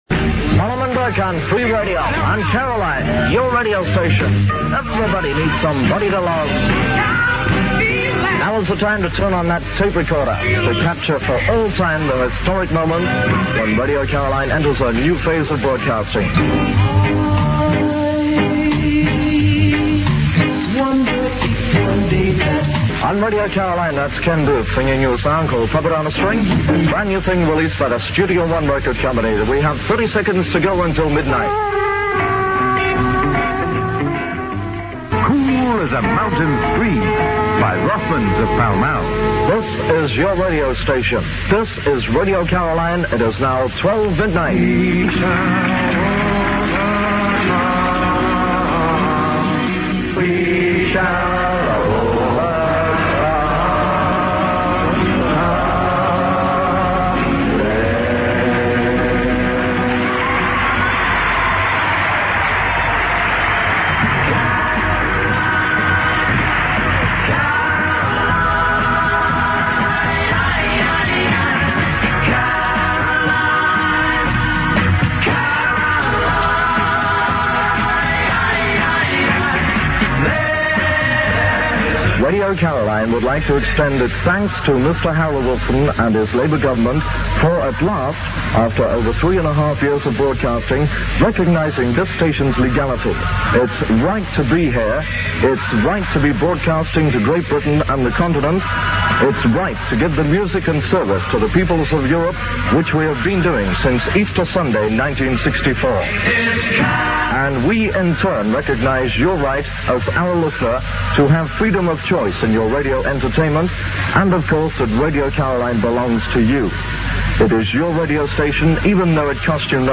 on Caroline South, midnight 14th August 1967